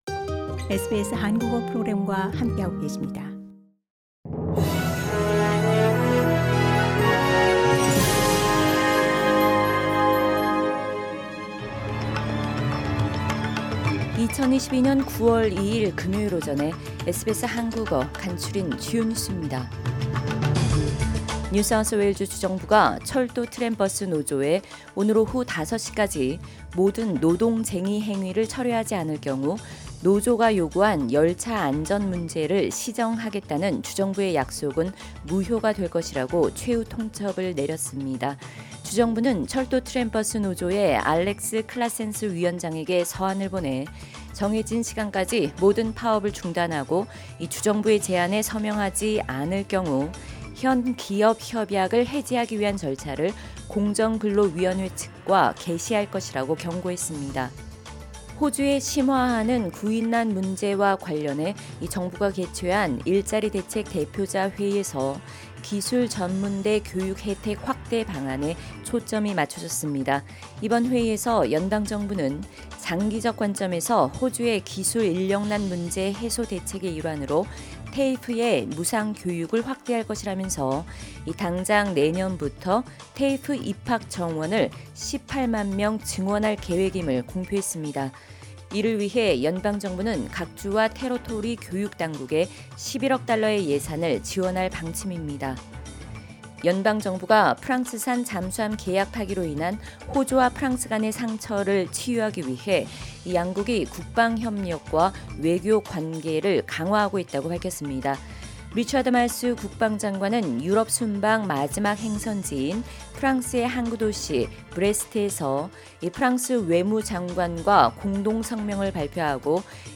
SBS 한국어 아침 뉴스: 2022년 9월 2일 금요일
2022년 9월 2일 금요일 아침 SBS 한국어 간추린 주요 뉴스입니다.